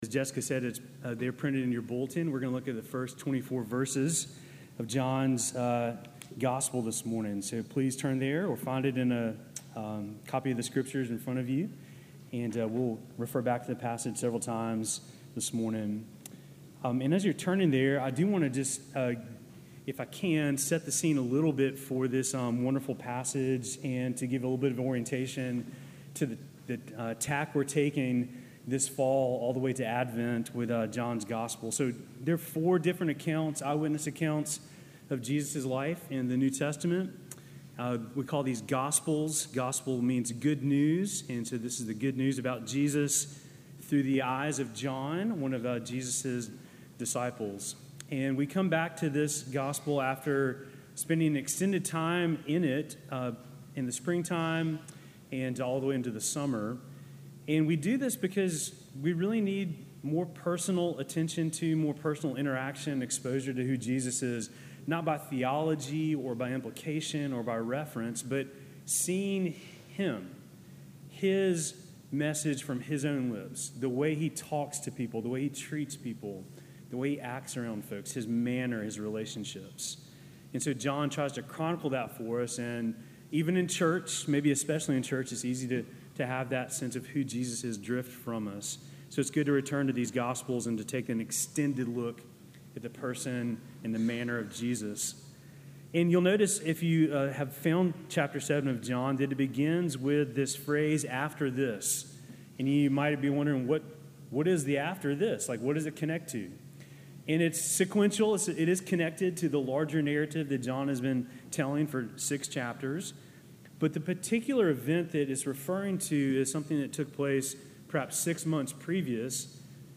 Sermon from August 1